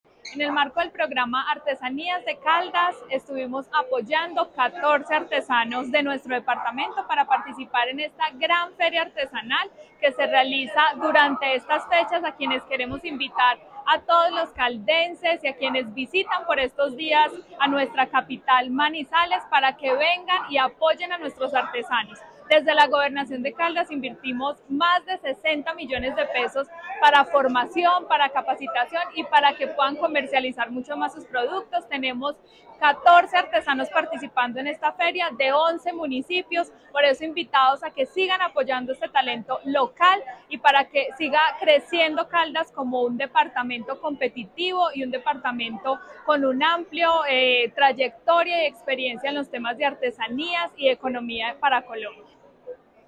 Secretaria de Desarrollo, Empleo e Innovación, Daissy Lorena Alzate.